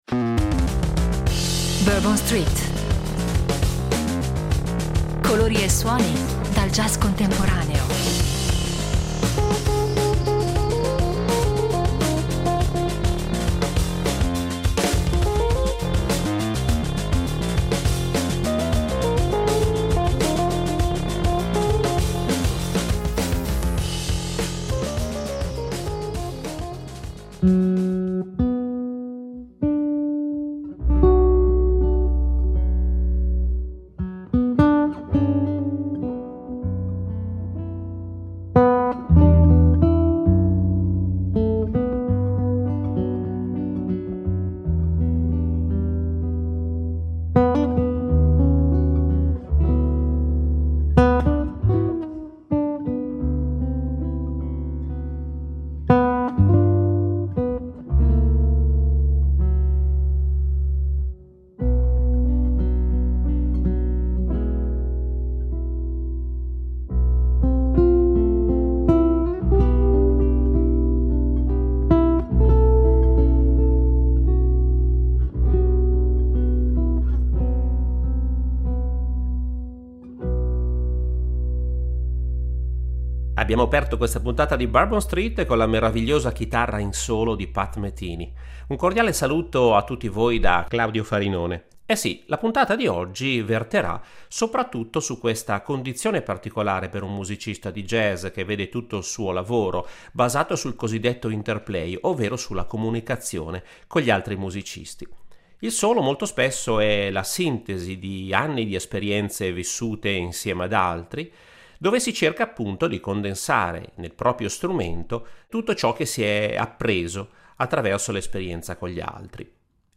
Una sorta di “diario armonico” e un’opera matura che mette in luce il suo lirismo, la sua inventiva armonica e una “anima” più profonda e introspettiva rispetto al suo abituale stile energico ed esplosivo.
Per concludere, una chicca in contrabbasso solo del funambolico polistrumentista israeliano Adam Ben Ezra.